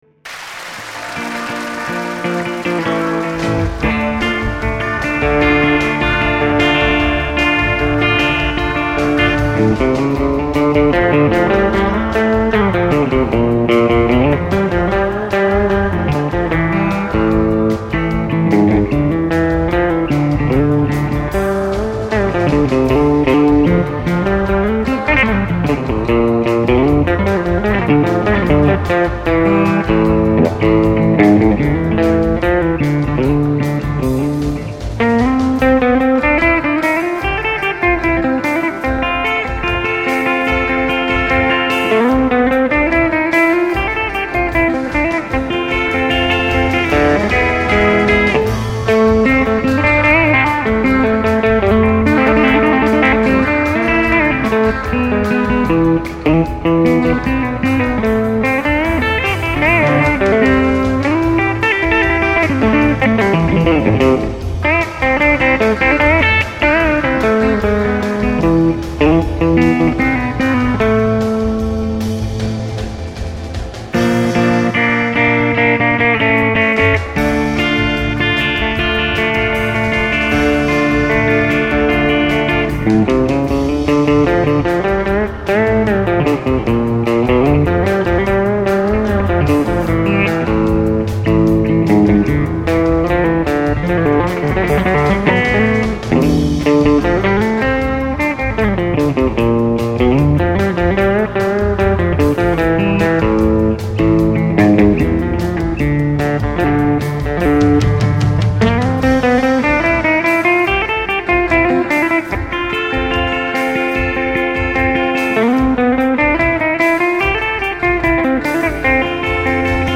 最初の印象が、なんか懐かしい音だなというもので、ソリッドギターにはない温かみのある音です。
…と、思って取り急ぎ７０曲ばかり演奏してから再生してみたら、録音レベルがオーバーしていて音がひずんでいました。
これらの録音はBOSSのＪＳ−１０という装置で、エフェクター内蔵のかなり優れものです。